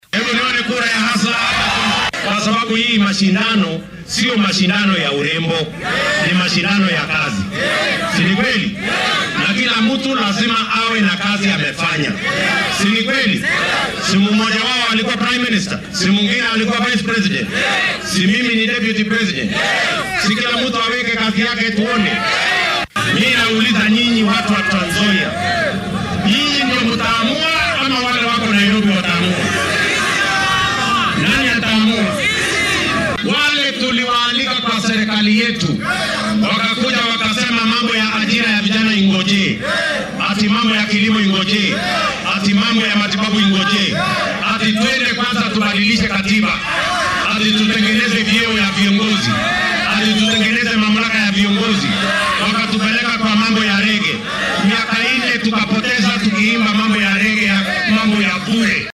William Ruto ayaa arrimahan ka hadlay xilli uu ku sugnaa ismaamulka Trans Nzoia isagoo dadweynaha halkaasi ku nool ka codsaday inay taageeraan qorshihiisa siyaasadeed ee ku saleysan in kobcinta dhaqaalaha wadanka meel hoose laga soo billaabo iyadoo si gaar ah loo garab qabanaya danyarta, ganacsatada yaryar iyo dhallinyarada shaqo la’aanta ah.